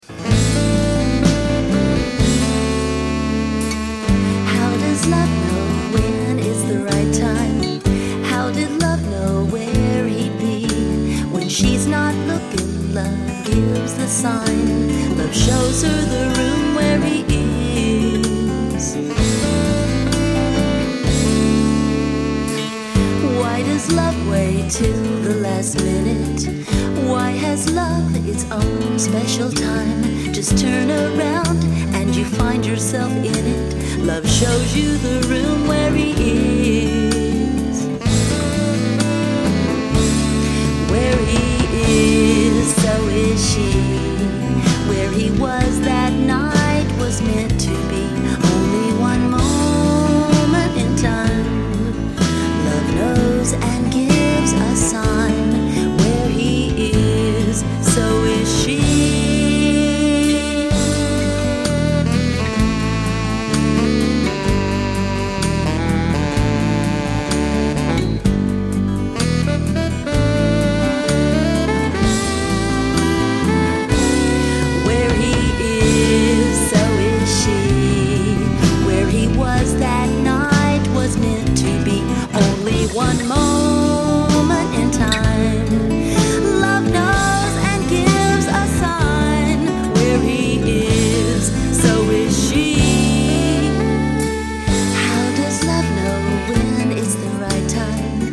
The saxophone and piano sounds
Recorded A/A/D.